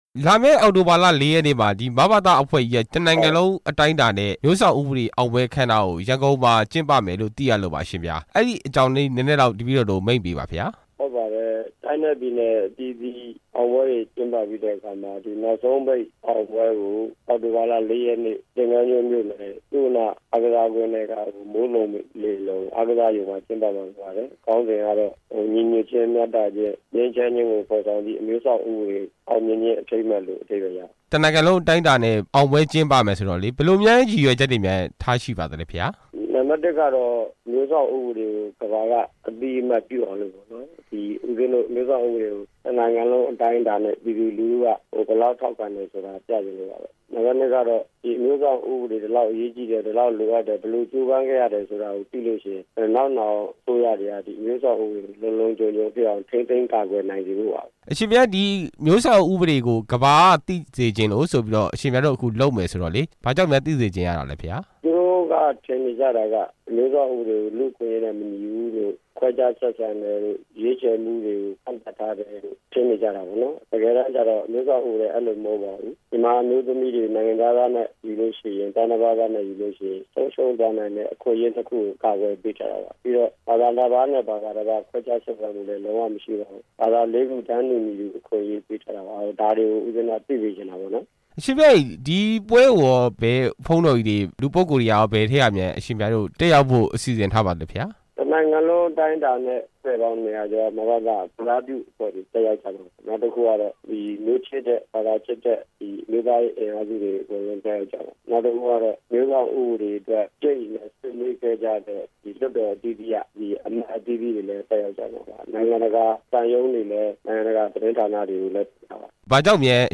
ဆရာတော် ဦးဝီရသူကို လျှောက်ထားမေးမြန်းချက်